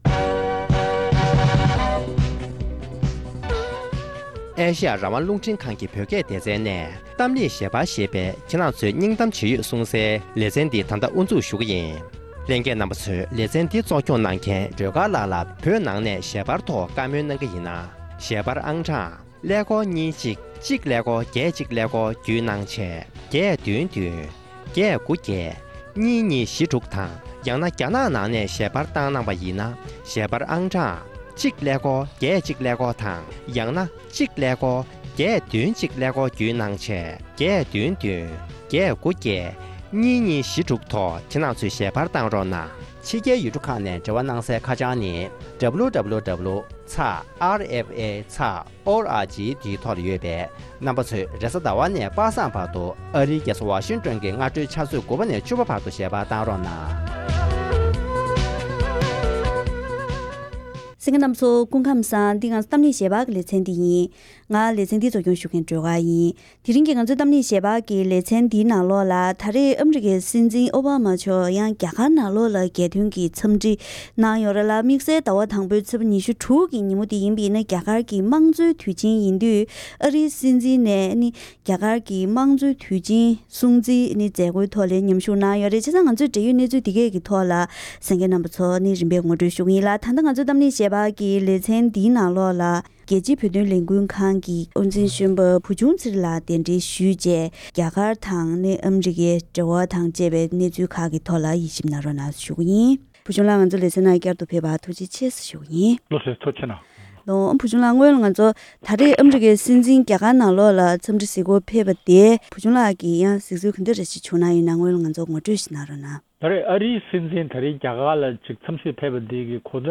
༄༅། །དེ་རིང་གི་གཏམ་གླེང་ཞལ་པར་ལེ་ཚན་འདིའི་ནང་ཨ་རིའི་སྲིད་འཛིན་རྒྱ་གར་ལ་རྒྱལ་དོན་གྱི་འཚམས་འདྲི་གཟིགས་སྐོར་ལ་ཕེབས་པའི་ཐོག་ནས་རྒྱ་གར་དང་ཨ་རི། རྒྱ་ནག་བཅས་ཡུལ་གྲུའི་འབྲེལ་བའི་སྐོར་ལ་བཀའ་མོལ་ཞུས་པ་ཞིག་གསན་རོགས་གནང་།།